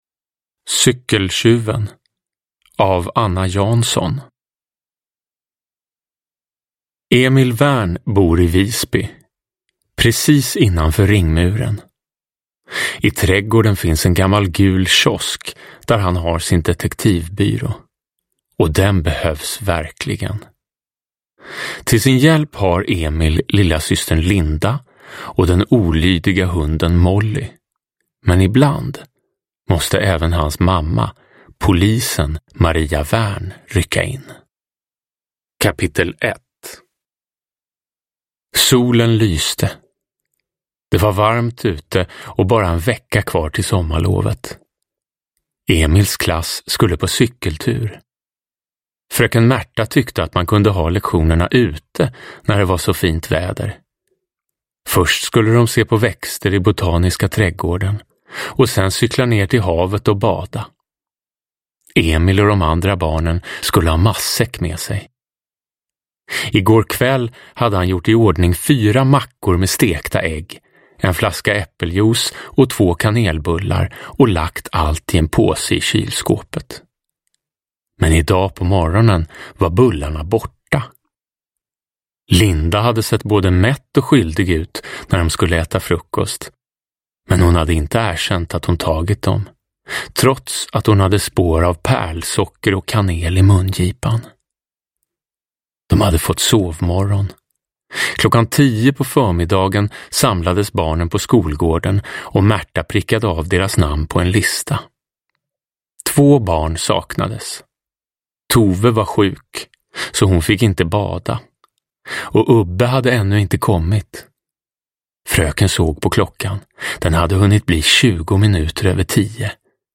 Cykeltjuven – Ljudbok – Laddas ner
Uppläsare: Jonas Karlsson